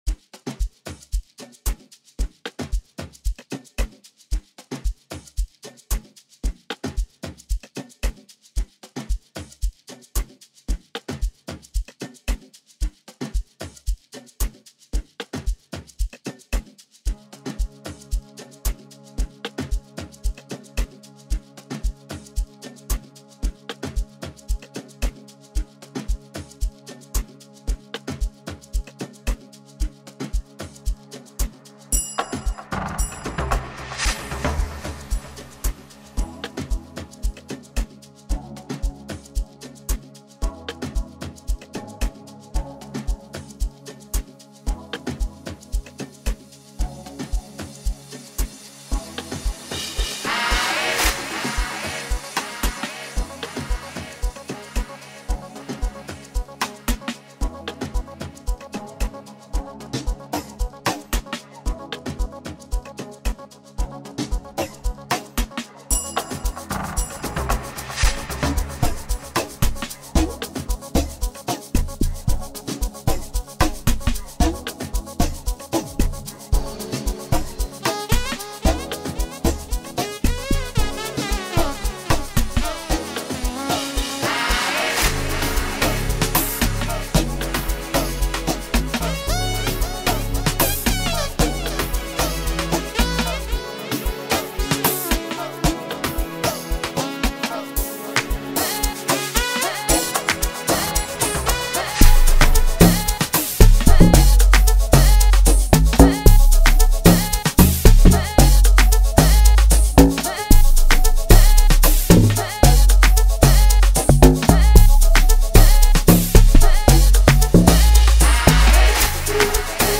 Foreign MusicSouth African
With his velvety smooth vocals and emotive delivery